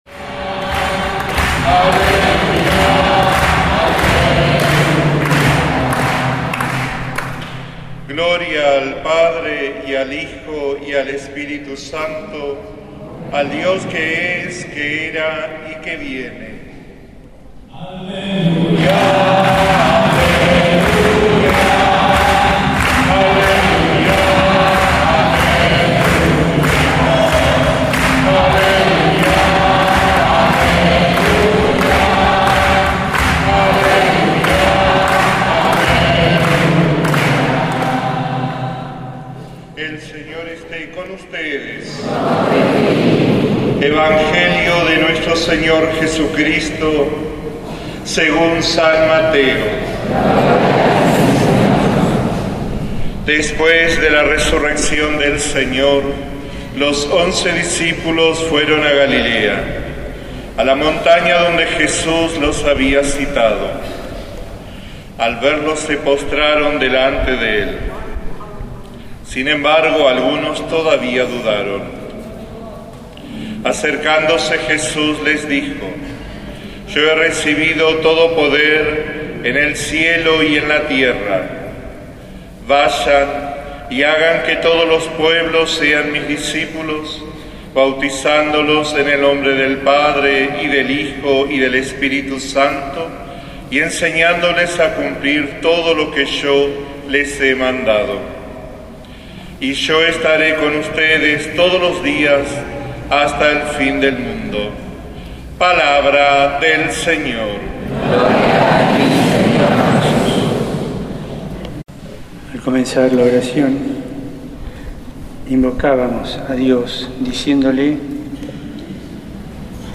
bergoglio-misa-rcc-2012.mp3